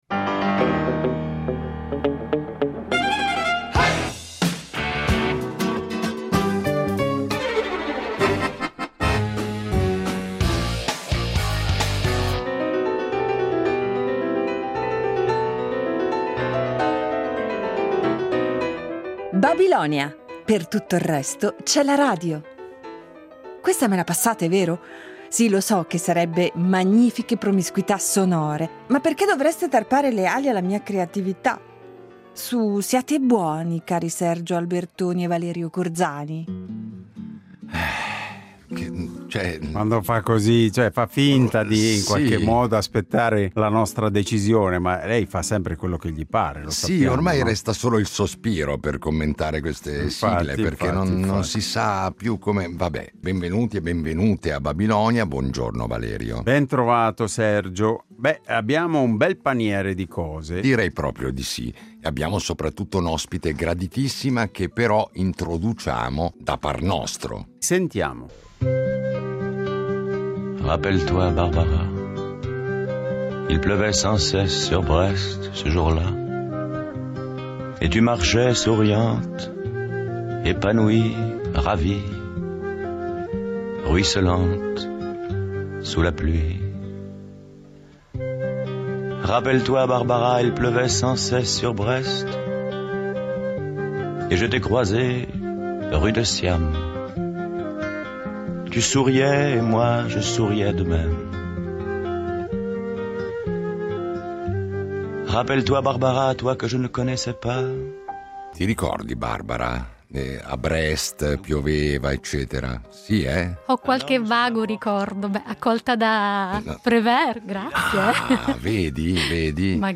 Incontro con l’artista